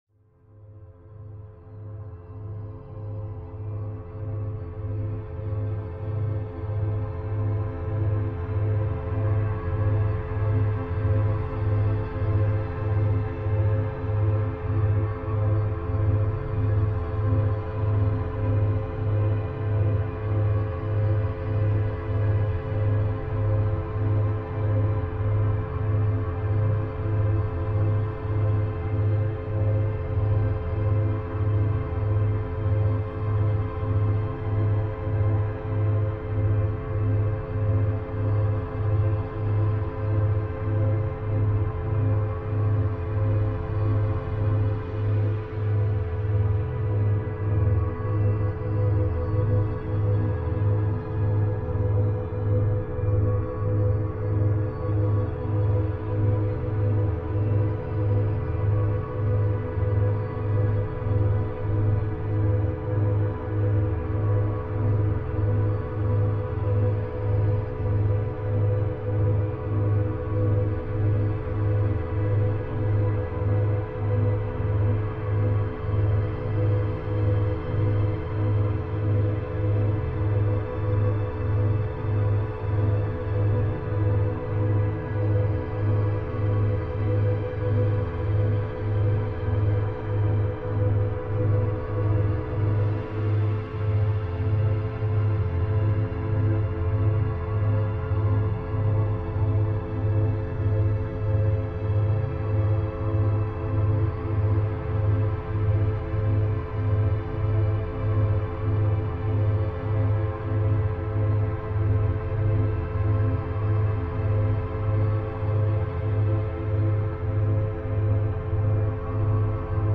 クラウンチャクラ瞑想 – 432 Hz | クラウンチャクラのバランスと癒し
勉強BGM